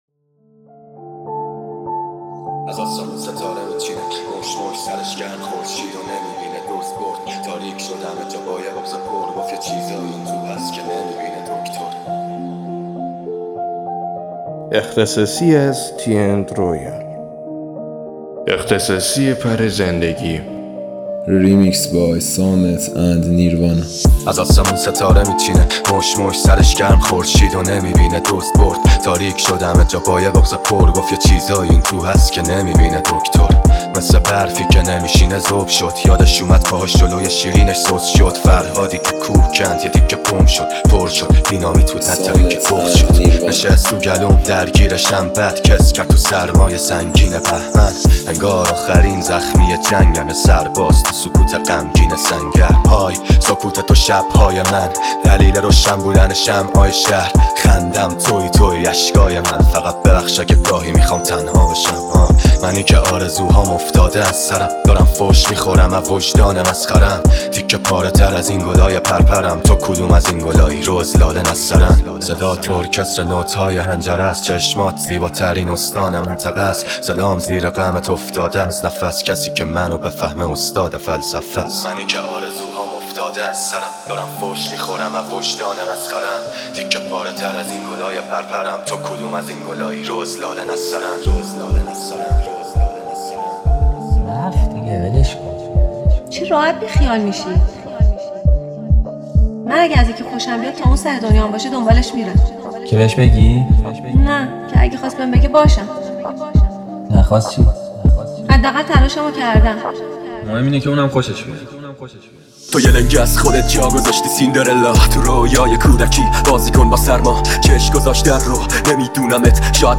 ریمیکس رپی
Remix Rapi